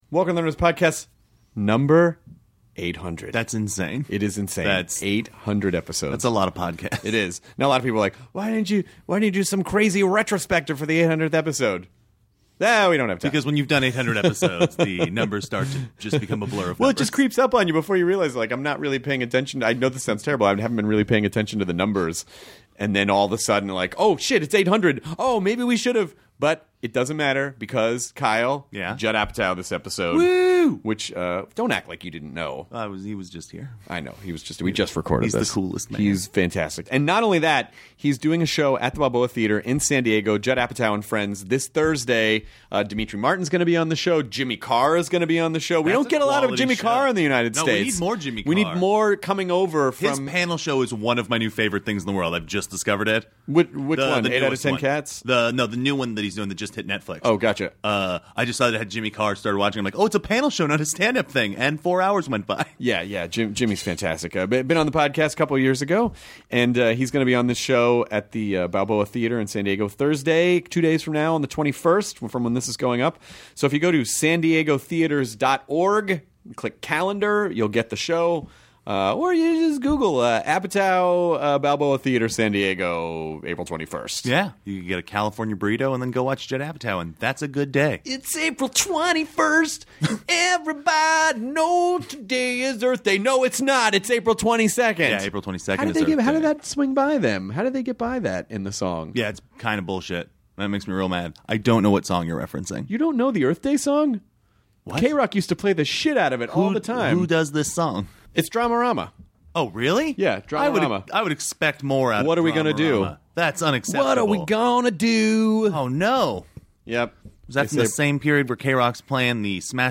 Judd Apatow (director, 40 Year Old Virgin, Knocked Up) returns to the Nerdist to chat with Chris